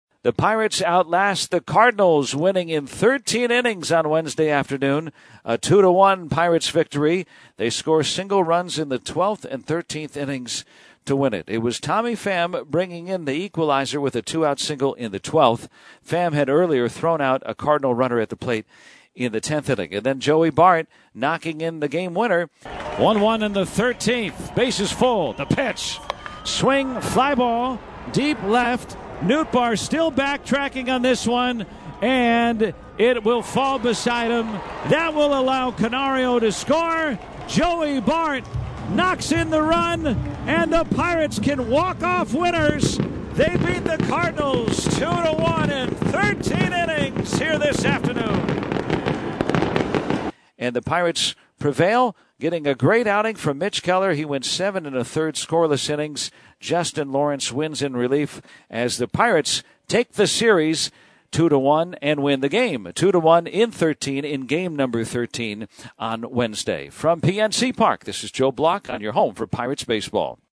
recaps an extra innings win on Wednesday afternoon.